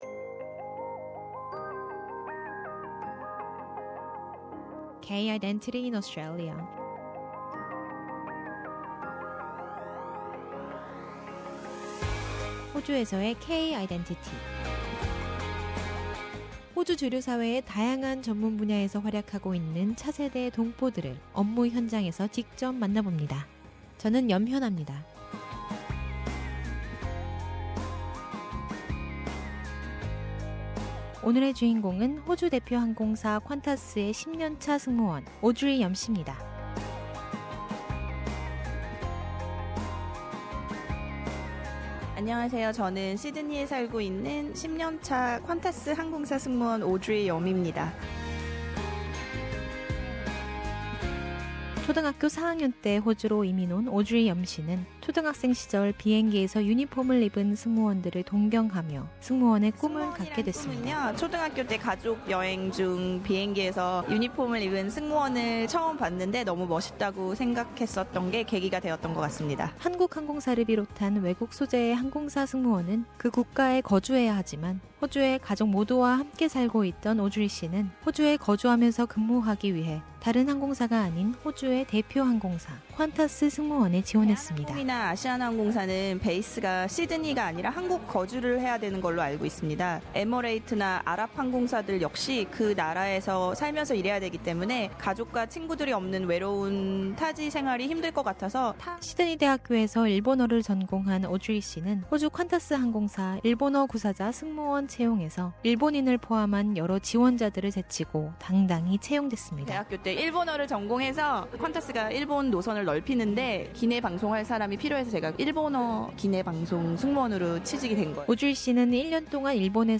K-Identity explores the identity issues through an interview with a variety of second-generation Korean Australian professionals working in various fields of the Australian mainstream.